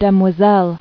[dem·oi·selle]